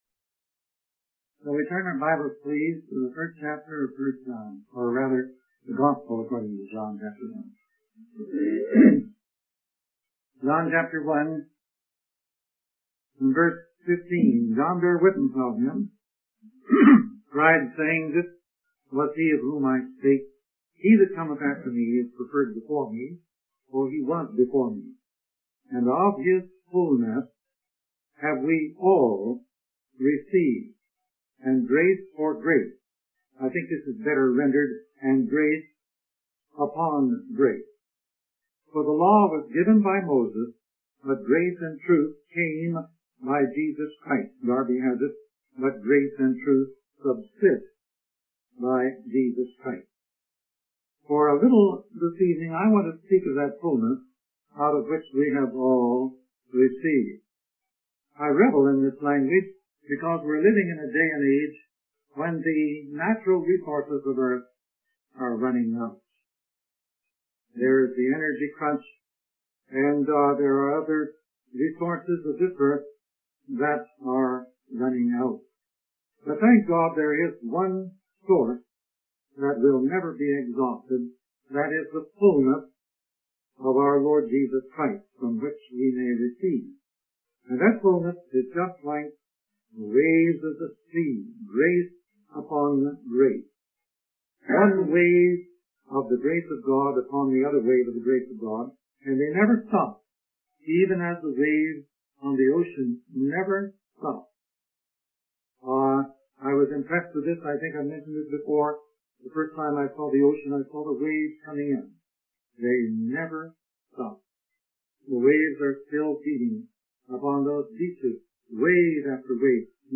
In this sermon, the preacher emphasizes the difference between the way the world gives and the way God gives. The world gives with the expectation of receiving something in return, while God gives out of the fullness of his heart.